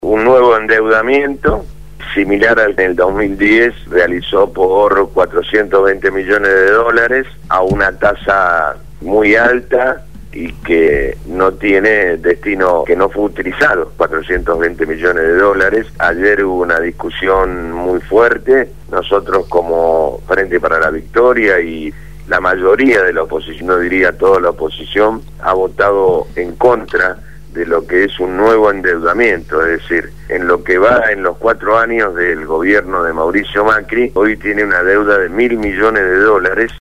Francisco «Tito» Nenna, Legislador Porteño por el Frente Para la Victoria, habló en el programa Punto de Partida (Lunes a viernes de 7 a 9 de la mañana) de Radio Gráfica FM 89.3 sobre la decisión de la Legislatura de endeudar -a pedido del Jefe de Gobierno- a la Ciudad por 500 millones de dólares.